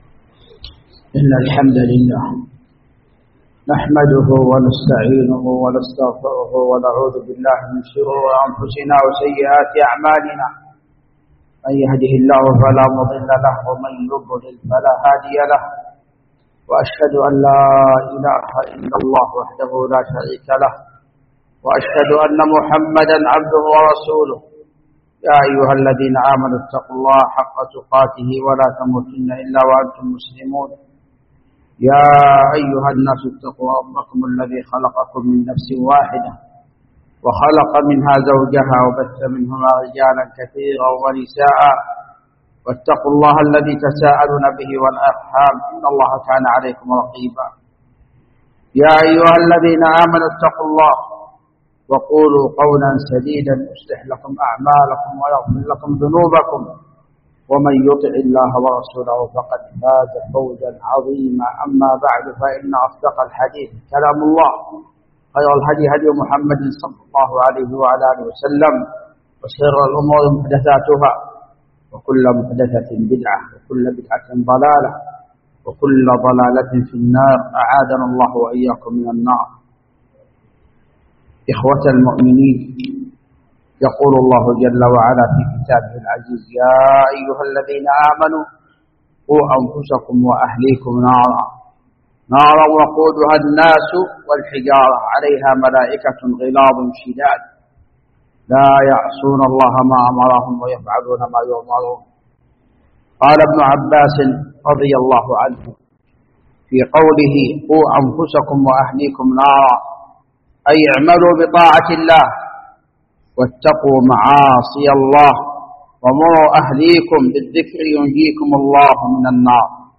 خطبة
جامع الملك عبدالعزيز باسكان الخارش بصامطة